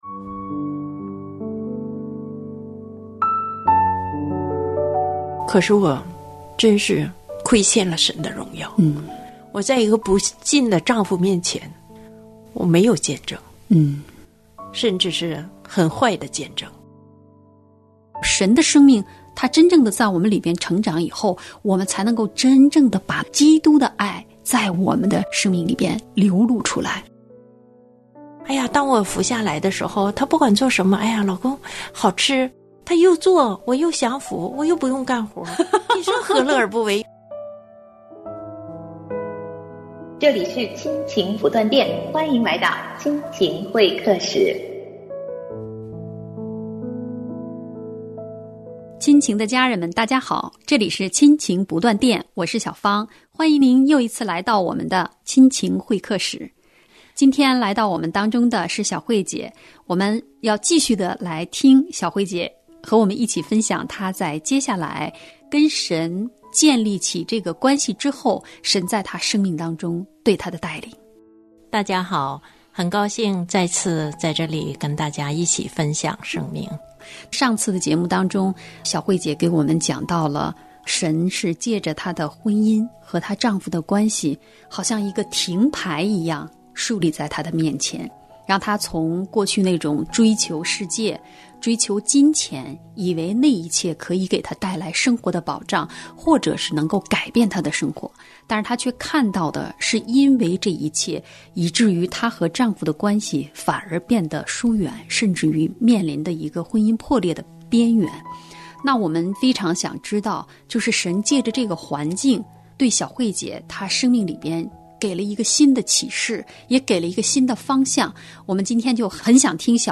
亲情会客室：采访一位非典型90后（4）从追梦到事奉，踏出跟随的脚步